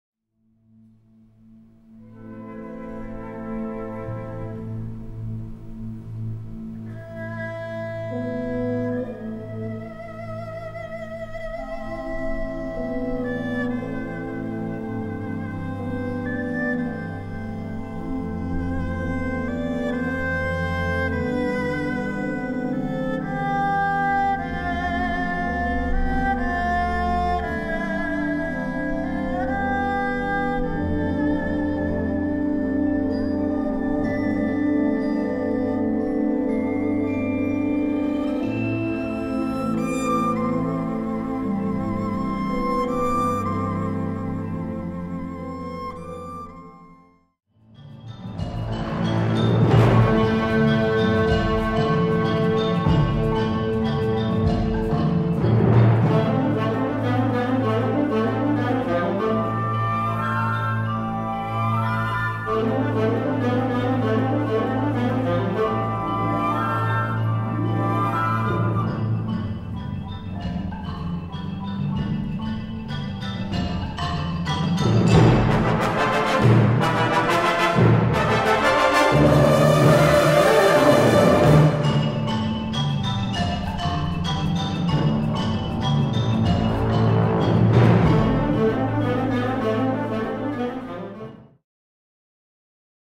15:00 Minuten Besetzung: Blasorchester Zu hören auf